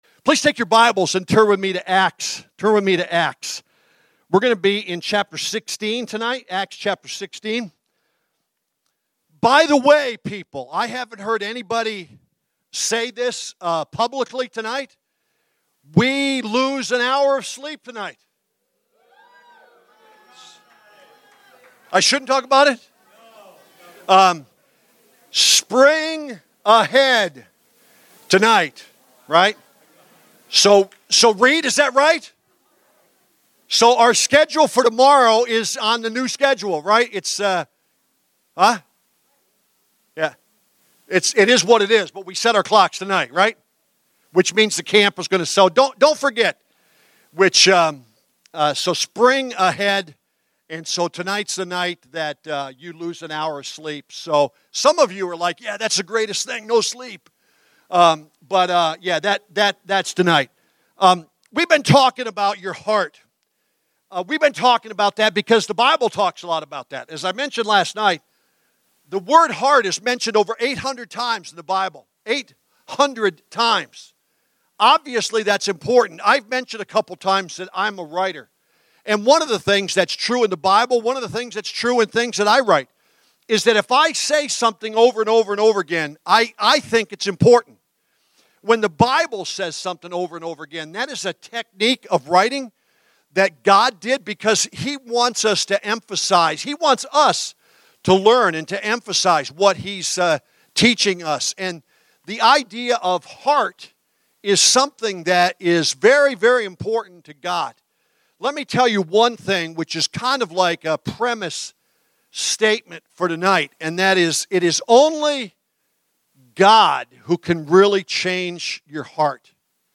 Saturday evening message at THAW &#8211